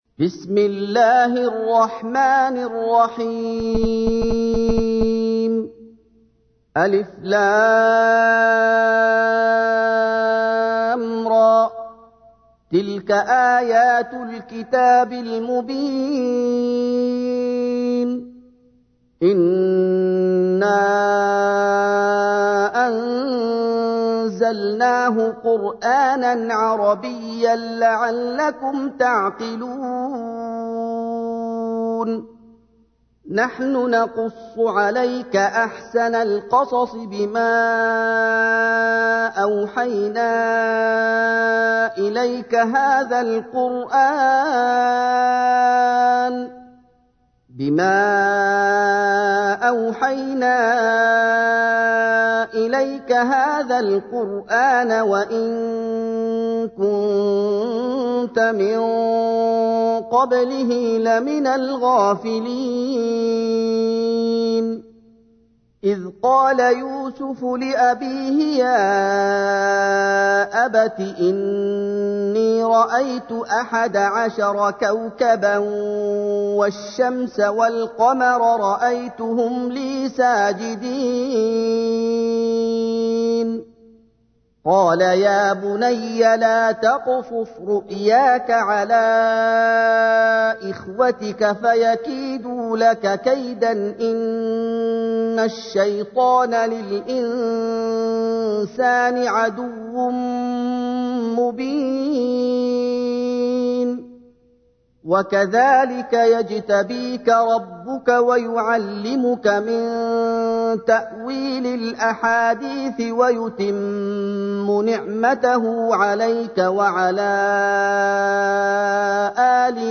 تحميل : 12. سورة يوسف / القارئ محمد أيوب / القرآن الكريم / موقع يا حسين